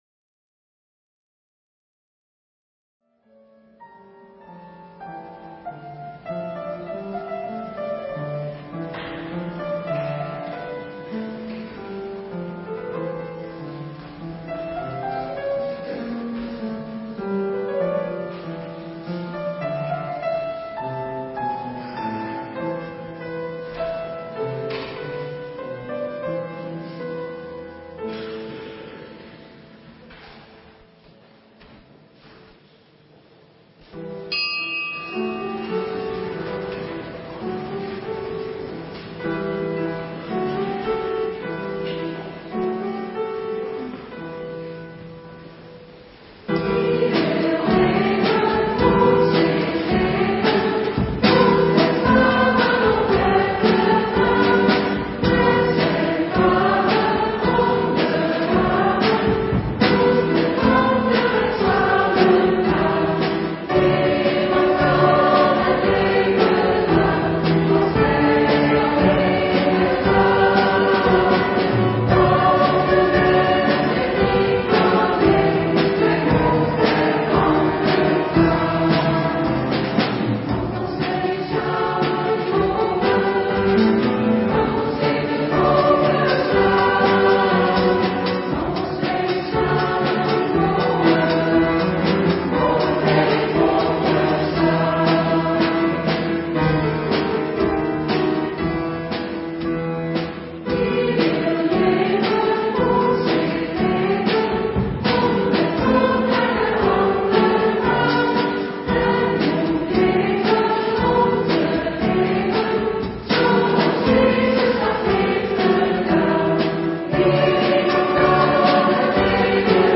Eucharistieviering beluisteren (MP3)